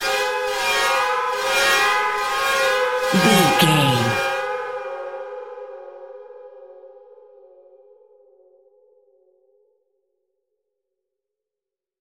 In-crescendo
Thriller
Atonal
scary
ominous
dark
suspense
haunting
eerie
horror
synth
keyboards
ambience
pads
eletronic